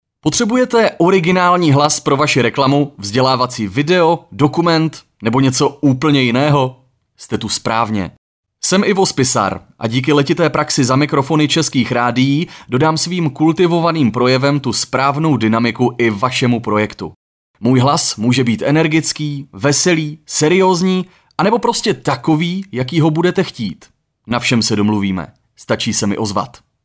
Profesionální mužský hlas (voiceover, reklama, dokument)
Dokážu se přizpůsobit a díky zkušenostem v nahrávání různých typů audia od reklamních spotů, přes dokumenty až po pozvánky na akce dokážu vystihnout kýženou náladu nahrávky.